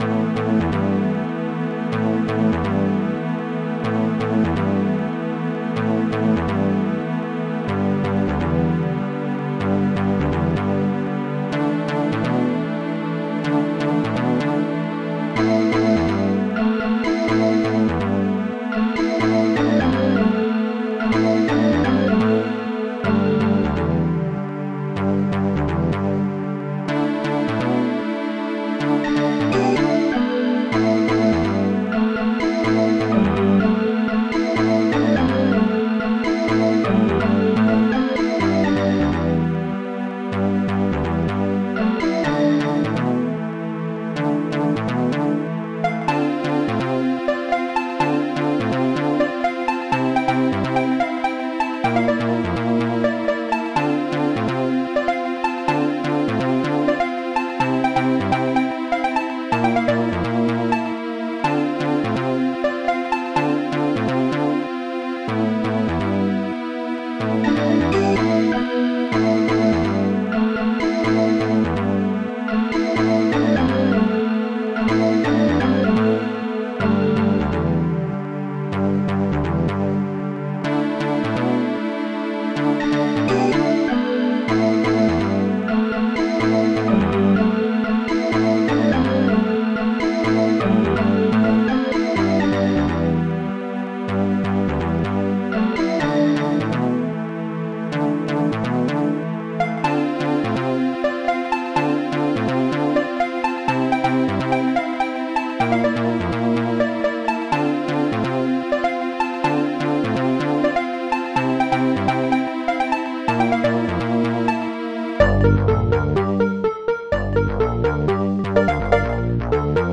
Instruments strings7 touch digiharp funbass